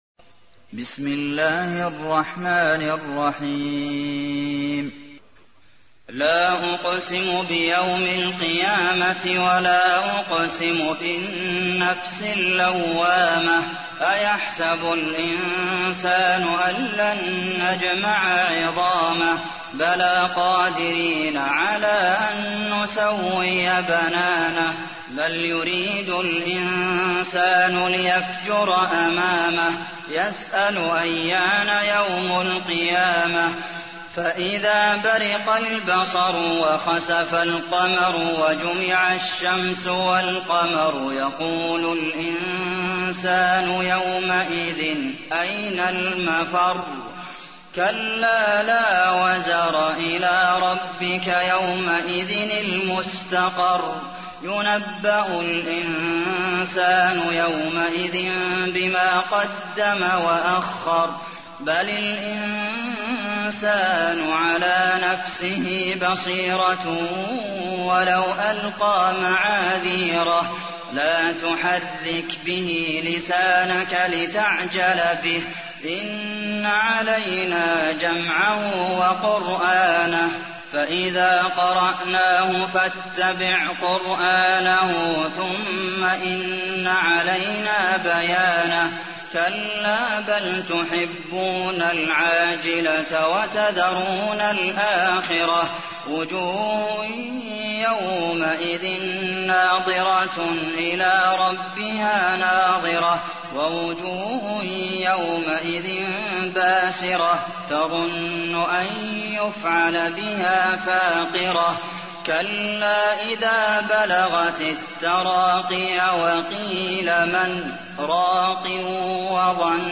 تحميل : 75. سورة القيامة / القارئ عبد المحسن قاسم / القرآن الكريم / موقع يا حسين